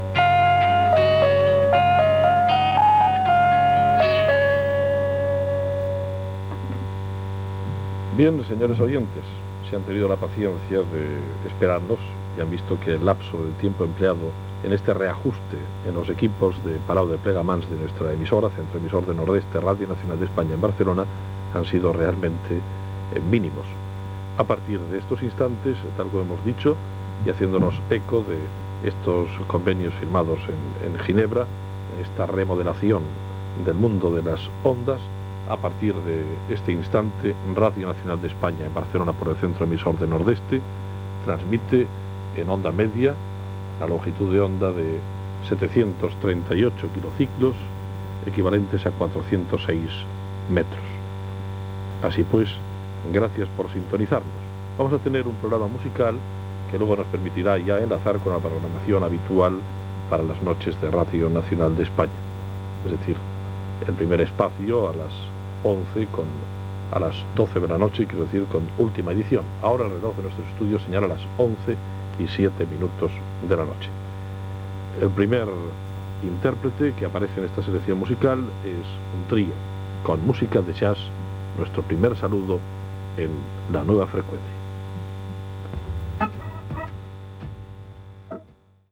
Sintonia. Represa de l'emissió després del reajustament de la freqüència.